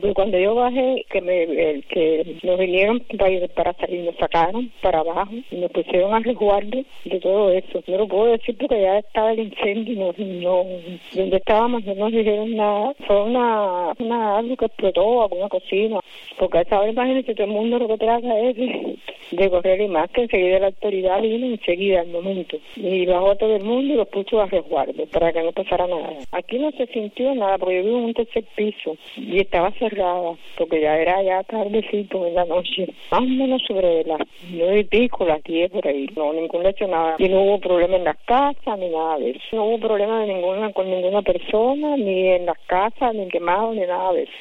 Una vecina de un edificio contiguo dijo a Radio Martí que de inmediato fueron evacuados los residentes y que no hubo fallecidos ni heridos en el siniestro.